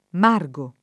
margo
margo [ m # r g o ] s. m. — latinismo poet. per «margine» (nel solo sing.: dal nominativo lat.): Giunta su ’l margo ella ristette, in forse [ J2 nta S u l m # r g o ella ri S t $ tte, in f 1 r S e ] (D’Annunzio)